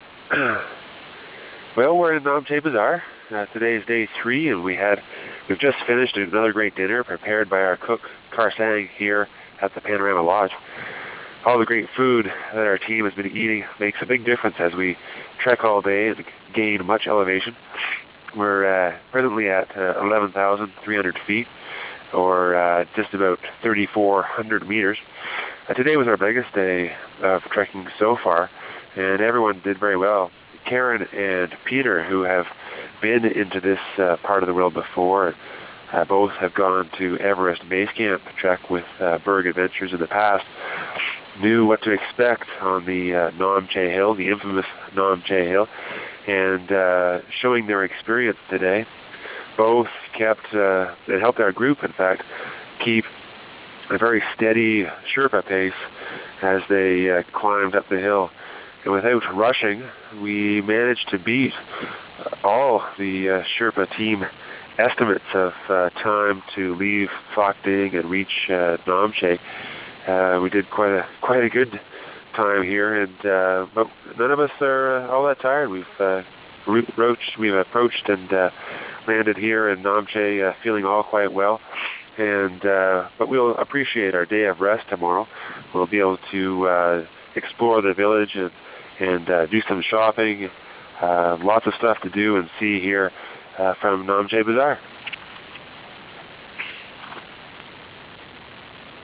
The village of Namche Bazaar